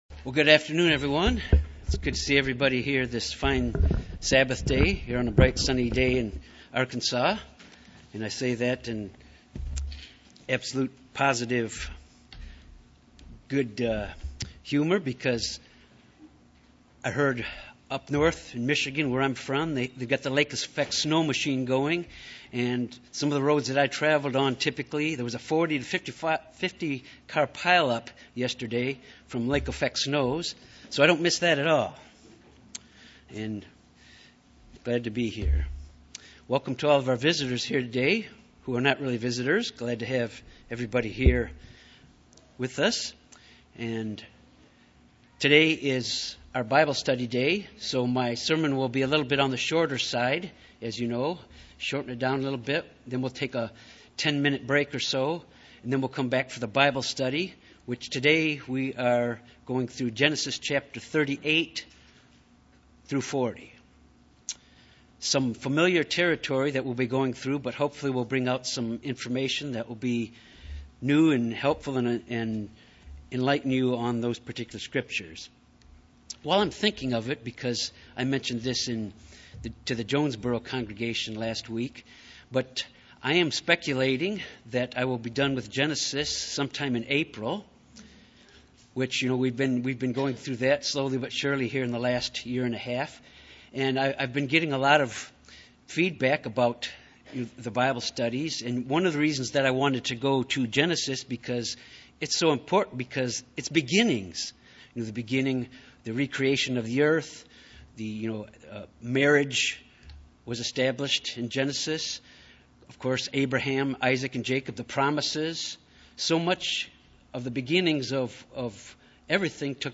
Ancient Israel was given warnings not to borrow from pagan practices with their worship of God. The root of the blending practice is examined in this sermon.